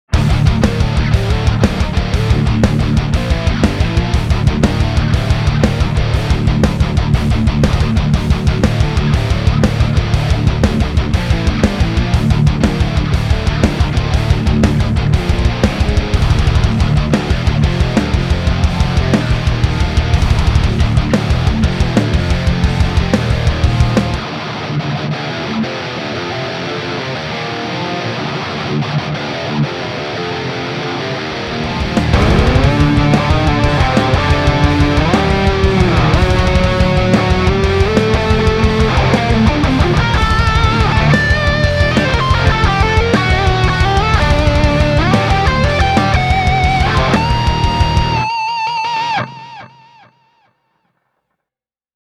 Voi olla, että kuvittelen (tai ehkä se johtuu korkeammasta vireestä), mutta minusta tuntuu, että saarnirunkoinen Ukonkirves kuulostaa aavistuksen verran kirkkaammalta kuin leppärunkoinen Ahti: